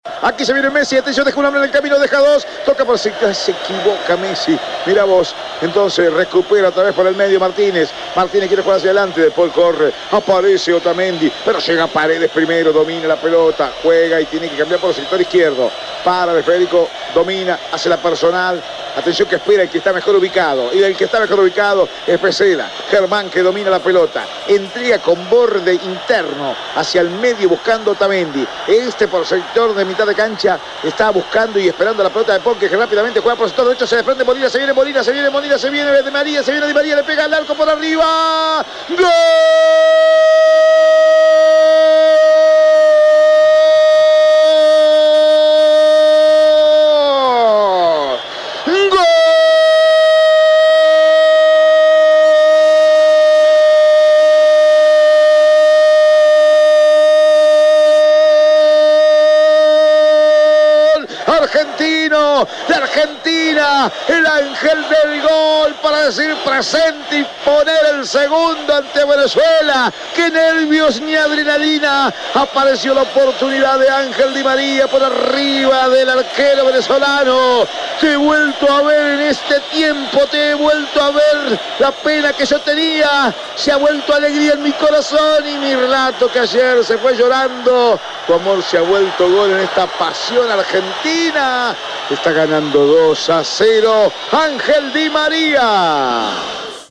02-GOL-DE-ARGENTINA.mp3